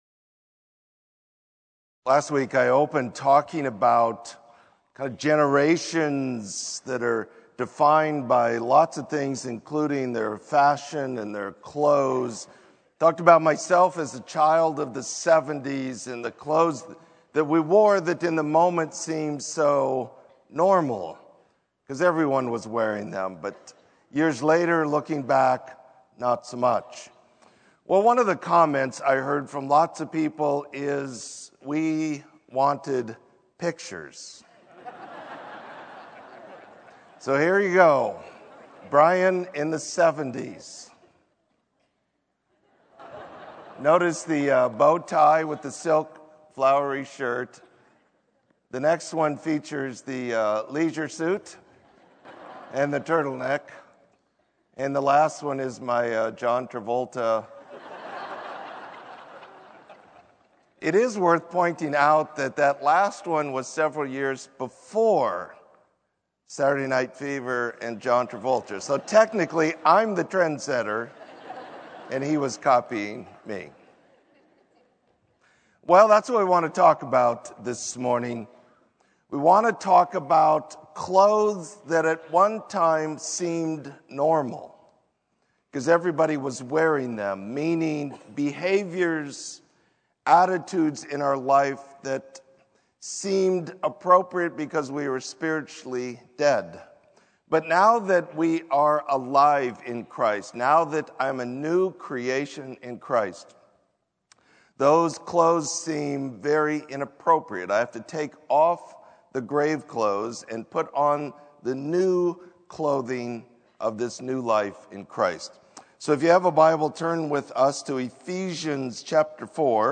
Sermon: Off With the Old, On With the New